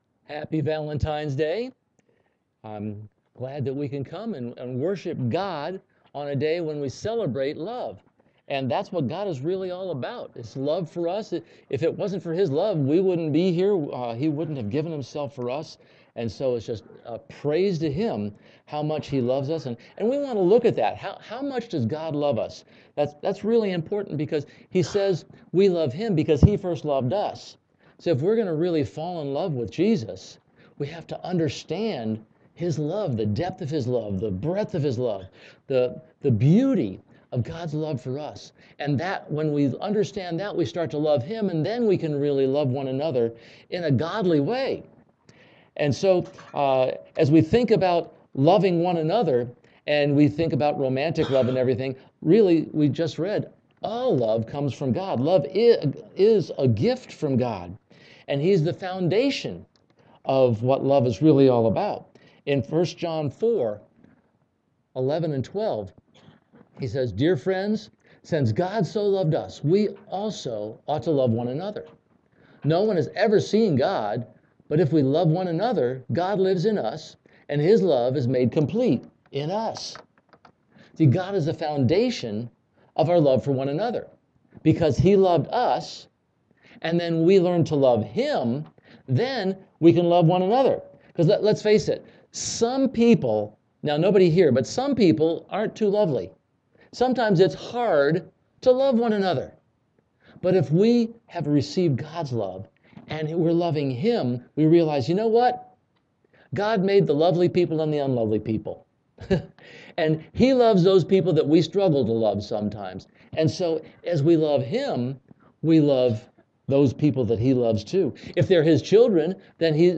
CCC Sermons Passage: 1 Corinthians 13:1-13 %todo_render% « Becoming an Atmosphere of Forgiveness Thrive